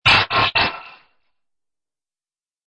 descargar sonido mp3 ruido metal laser